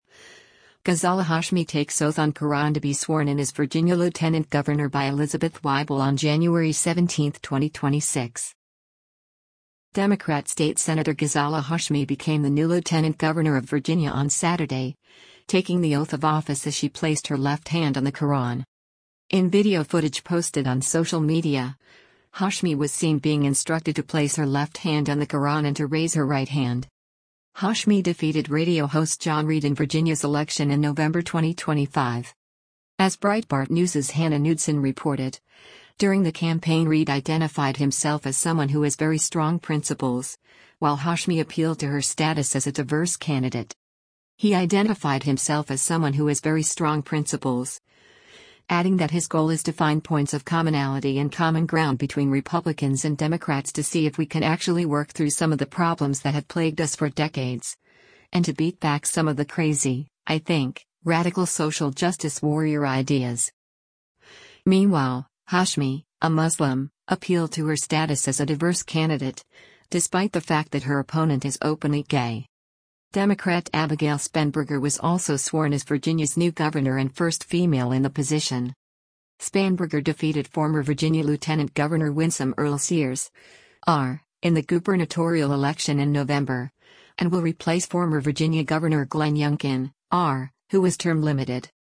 In video footage posted on social media, Hashmi was seen being instructed to place her left hand on the Quran and to raise her right hand.